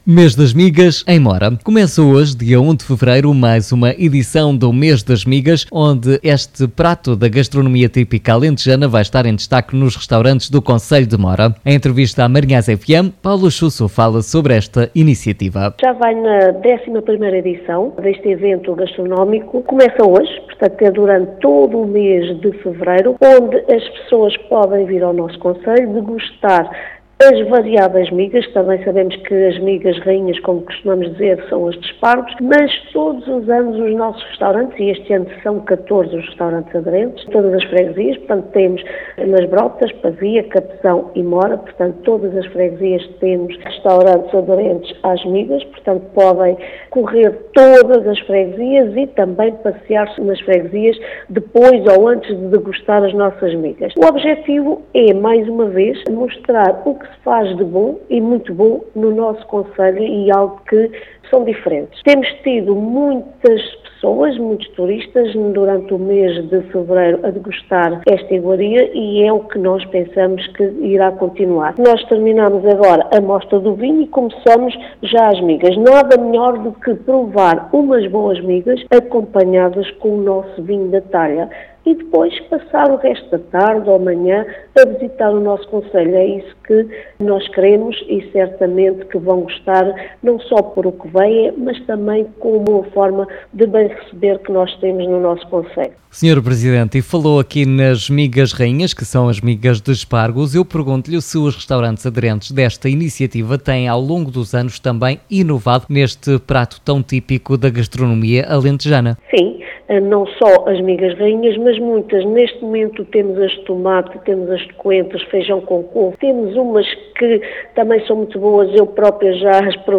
Em entrevista à MarinhaisFM, a Presidente da Autarquia, Paula Chuço referiu que esta iniciativa decorre durante o mês de fevereiro e os apreciadores da boa gastronomia alentejana podem deslocar-se até Mora, mais concretamente a qualquer um, entre 14, dos restaurante aderentes para provar as migas confecionadas das mais diferentes maneiras.
Escute, aqui, as declarações de Paula Chuço: